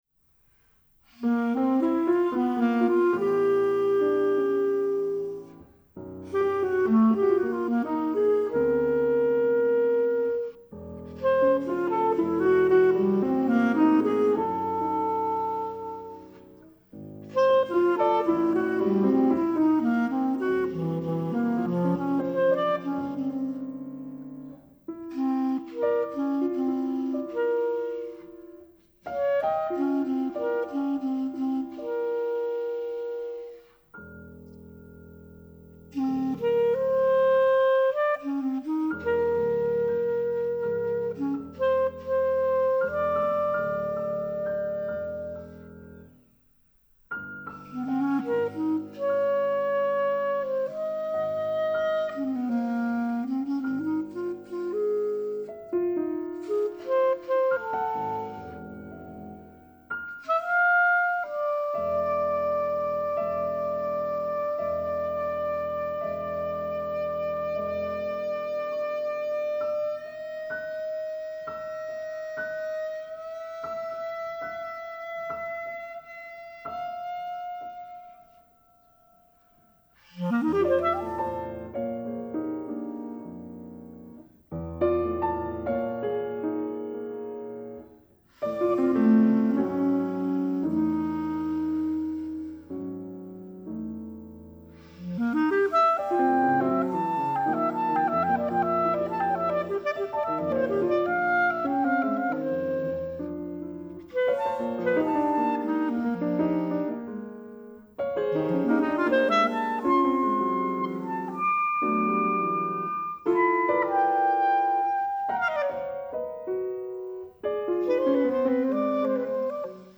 Recorded at Saal3/rbb, Berlin